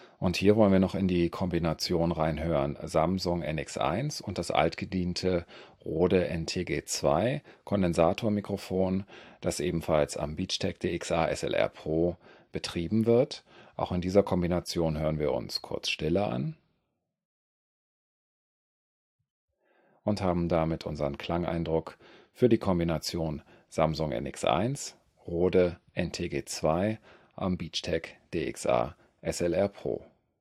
Hier inklusive Denoiser:
Samsung NX1 mit Beachtek DXA-SLR PRO und Rode NTG2 (Kondensator Richtmikro Phantomspannung)
SamsungNX1_NTG2Rode_norm_denoise.wav